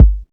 Kick 2.wav